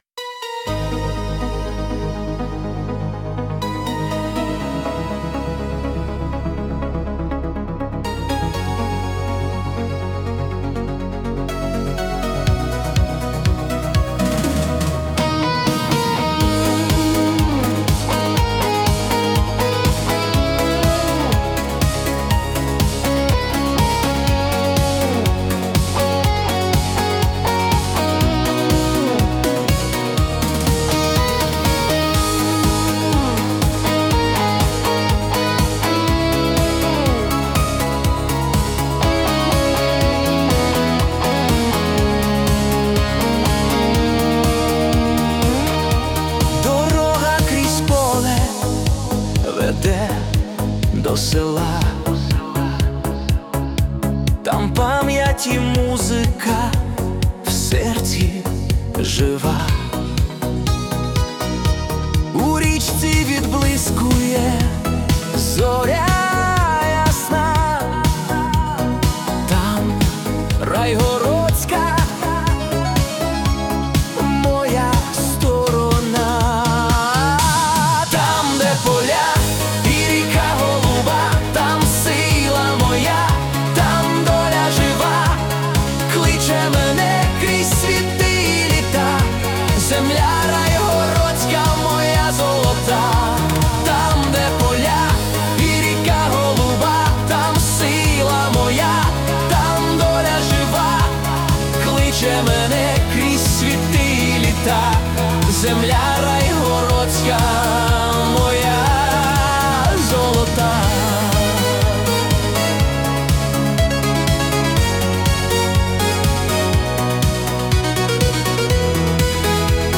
🎵 Жанр: Italo Disco / Nostalgia
Музика передає пульс життя, яке триває попри все.